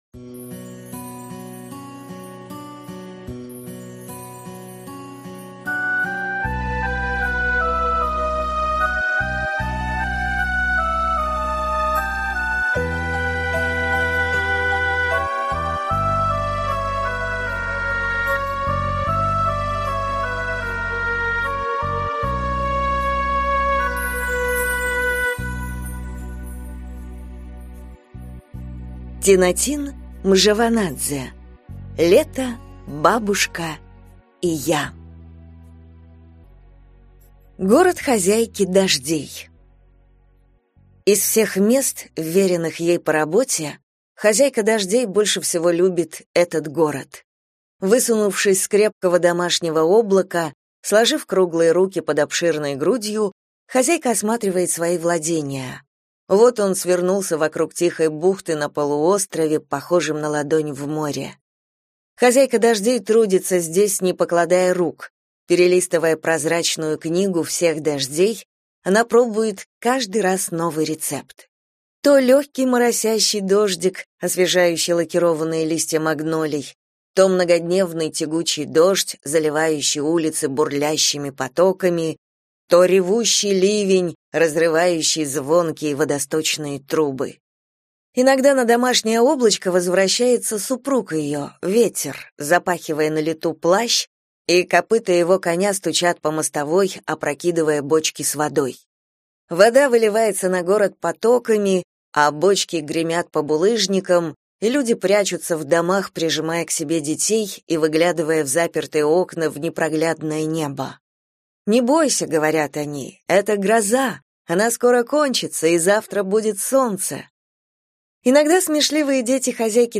Аудиокнига Лето, бабушка и я | Библиотека аудиокниг